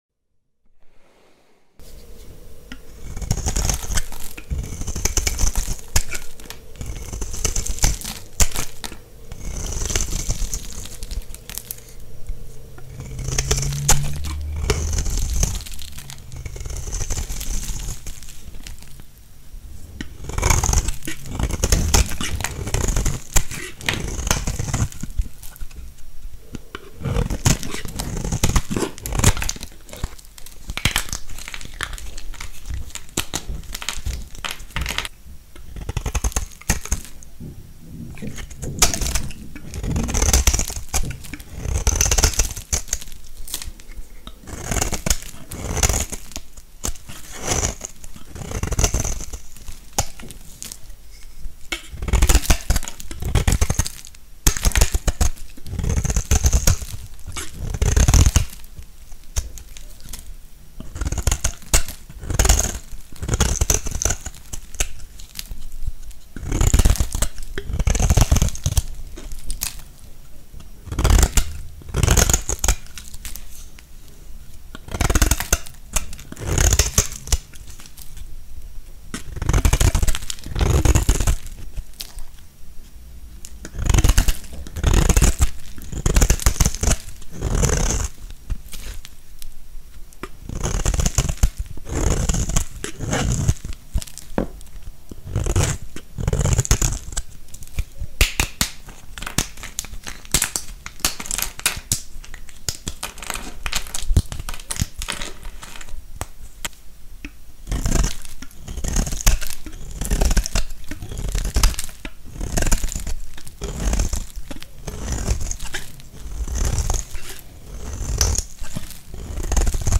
Unlimited ASMR Soap cutting _ sound effects free download